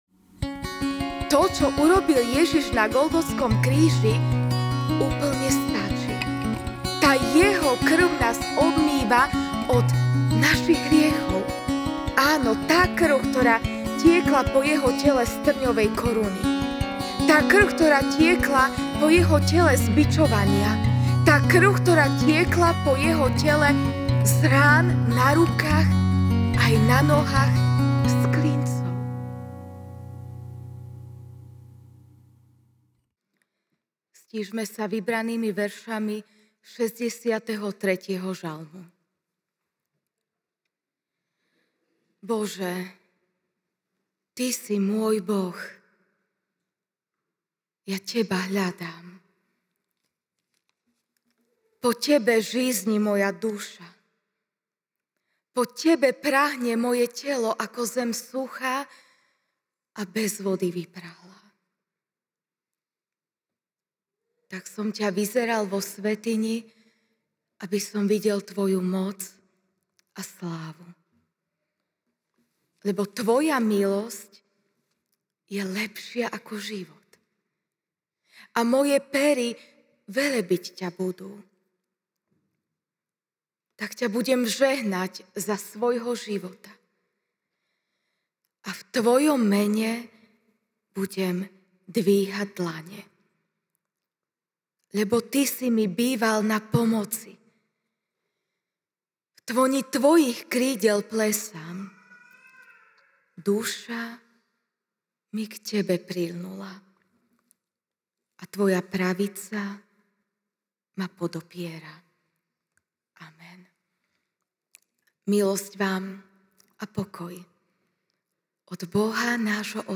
apr 18, 2025 Veľký piatok večer MP3 SUBSCRIBE on iTunes(Podcast) Notes Sermons in this Series Večerná kázeň: J(15, 13-14) „ Nikto nemôže väčšmi milovať, ako keď život položí za svojich priateľov.